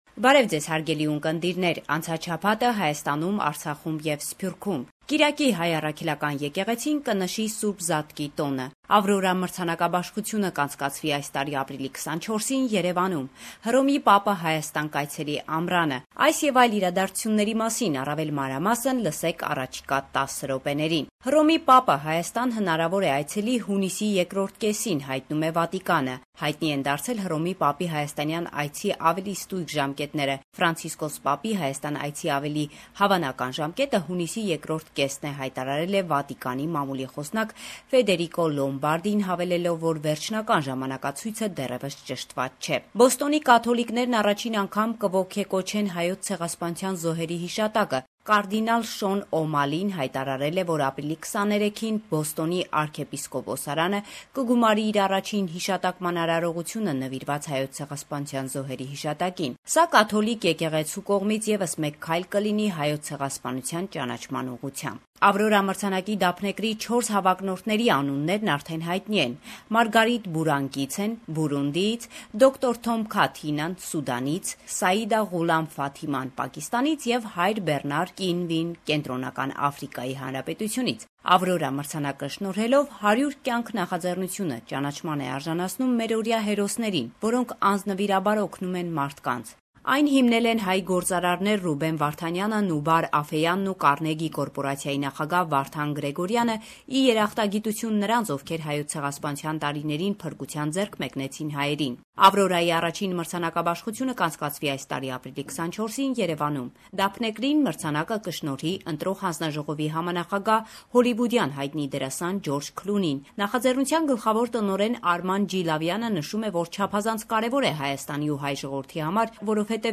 LATEST NEWS – 22 March 2015